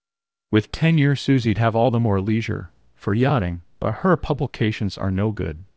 text-to-speech voice-cloning